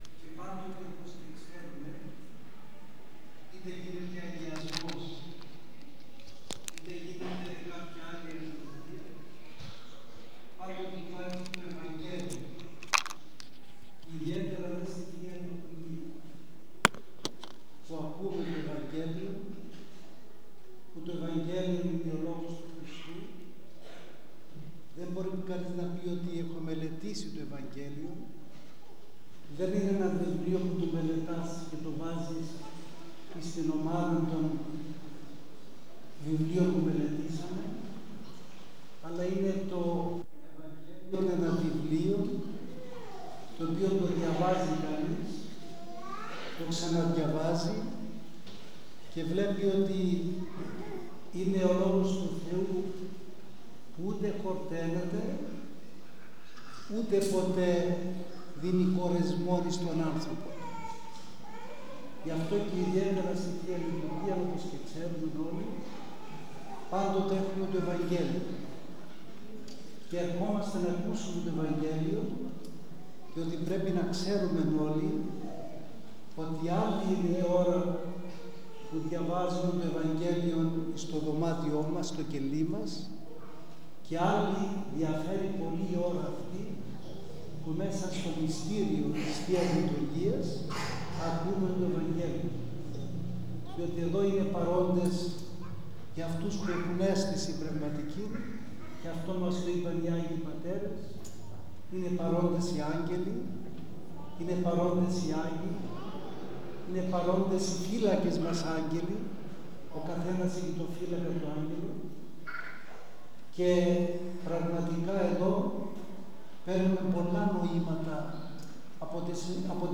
Νωρίτερα στον Ιερό Ναό Αγίας Παρασκευής Νέας Κρήνης ο Πανοσιολογιώτατος Καθηγούμενος τέλεσε την Θεία Λειτουργία πλαισιούμενος από τους ιερείς του Ναού και κήρυξε τον θείο λόγο αναφερόμενος στο σημερινό Ευαγγέλιο.